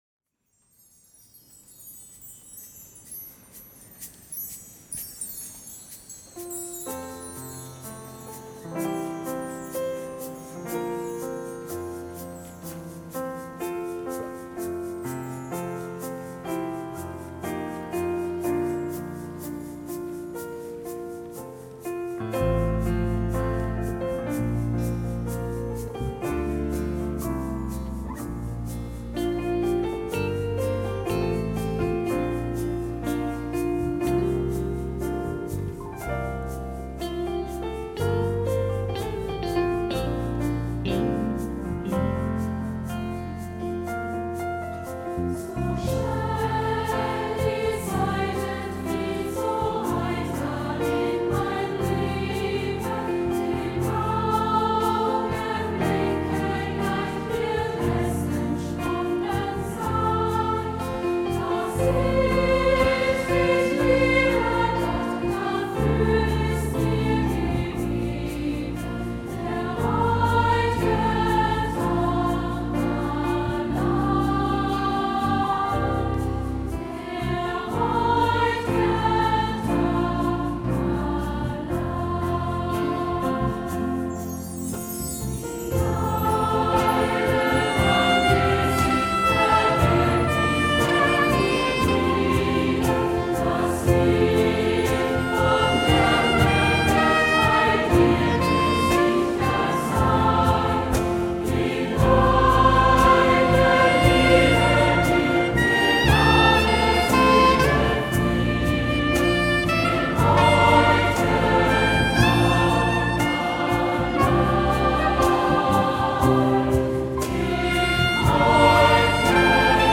Die Vertonung eines ihrer bekanntesten Texte ist darum speziell für ihn entstanden und wurde in der vorliegenden Form beim Dankgottesdienst anlässlich seiner Emeritierung gesungen.